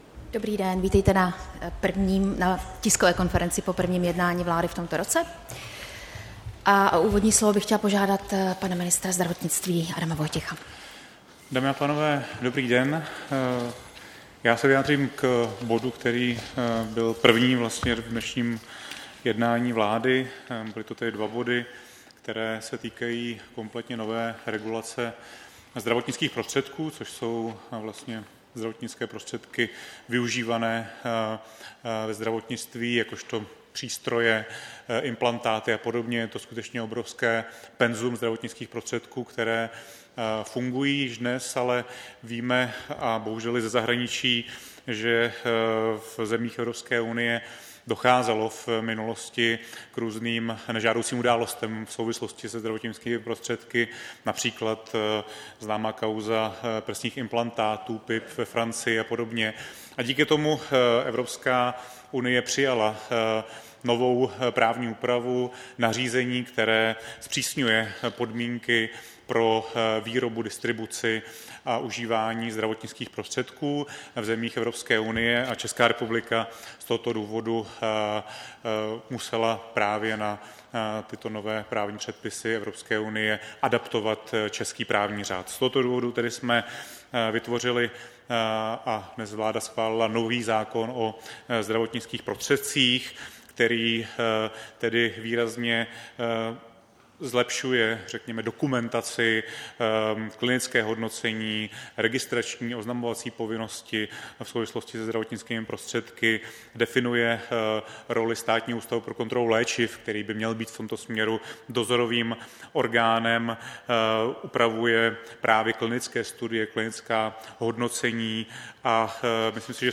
Tisková konference po jednání vlády, 6. ledna 2020